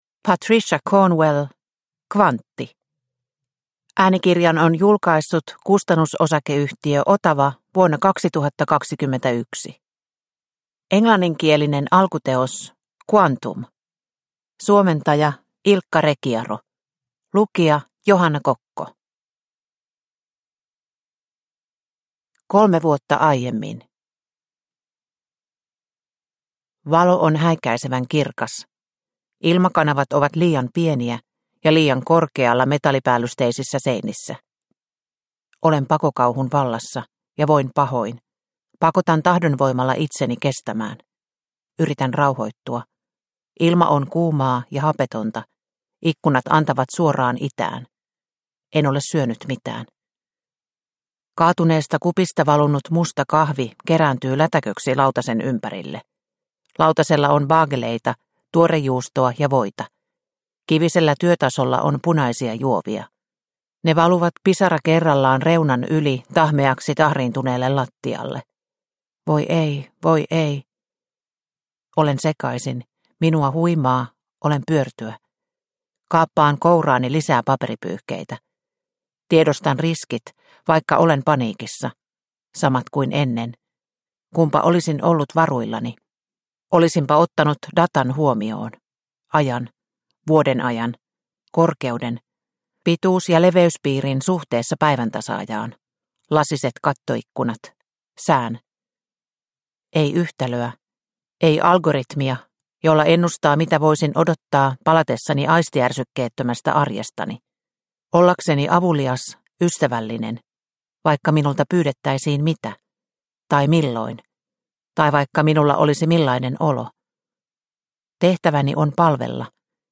Kvantti – Ljudbok – Laddas ner